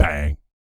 BS BANG 02-R.wav